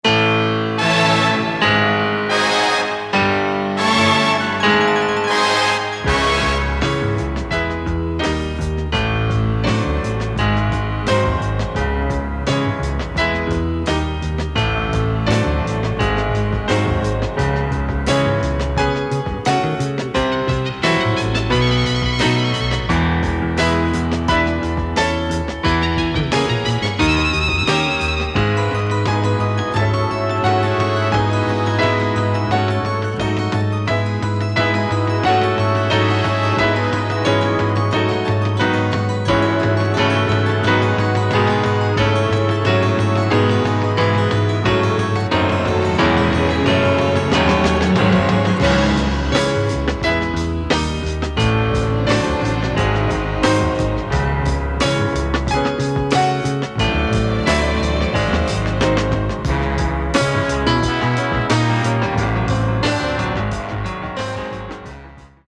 electric piano. Killer samples and breaks.